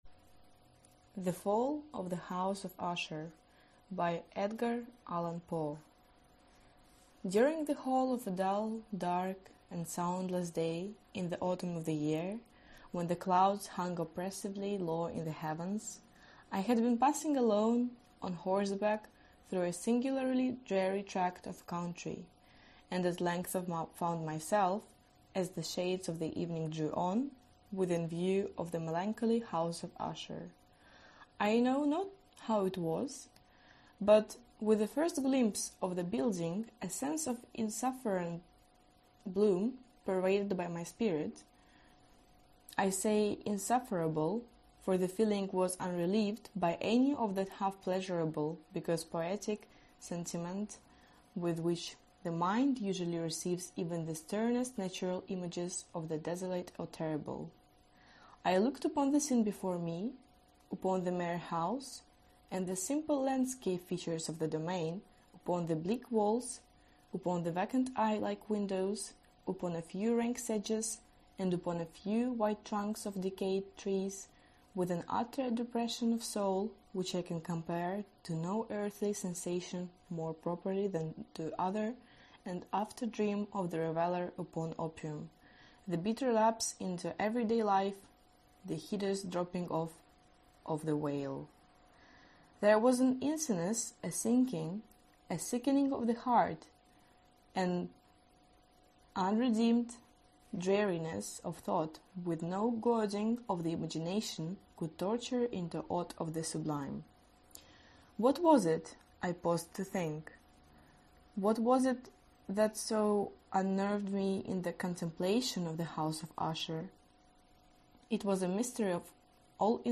Аудиокнига The Fall of the House of Usher/Падение дома Эшер | Библиотека аудиокниг